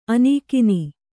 ♪ anīkini